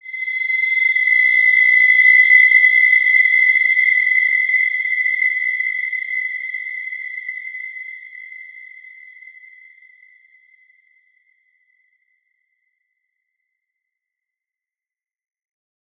Wide-Dimension-C6-mf.wav